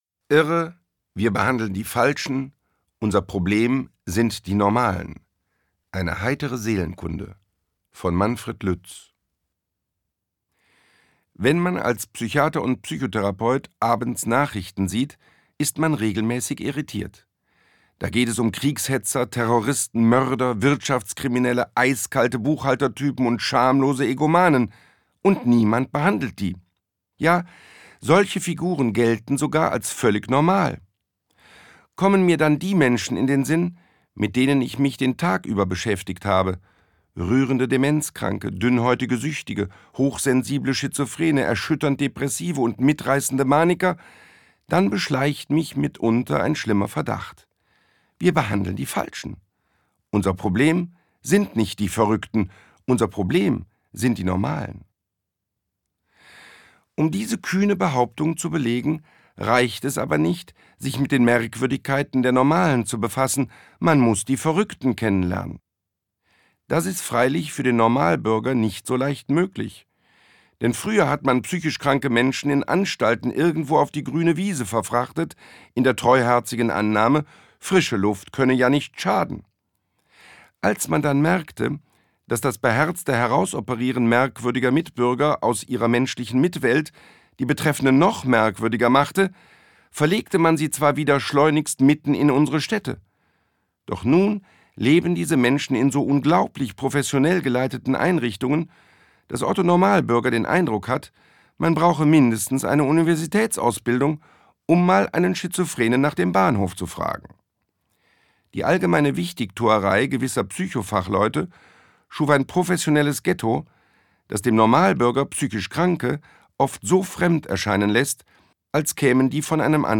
Manfred Lütz (Sprecher)